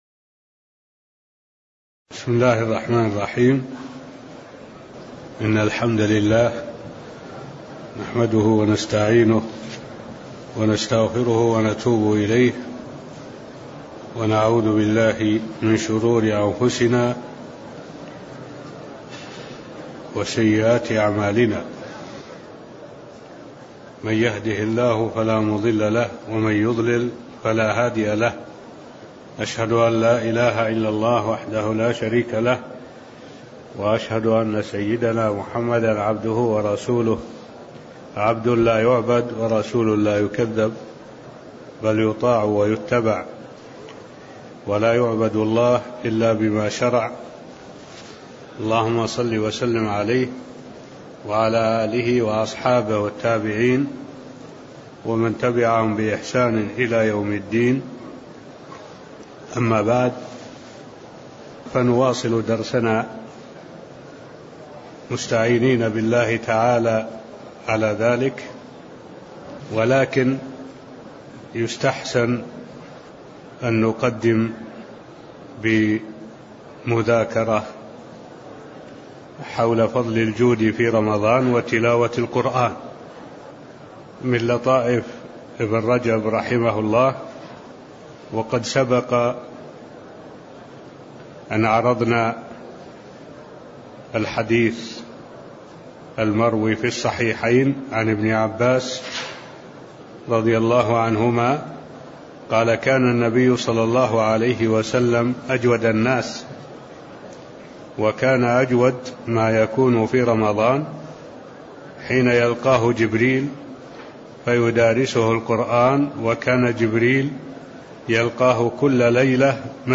المكان: المسجد النبوي الشيخ: معالي الشيخ الدكتور صالح بن عبد الله العبود معالي الشيخ الدكتور صالح بن عبد الله العبود كتاب الصيام من قوله: (ومنها أن الصائم يدع طعامه وشرابه لله) (12)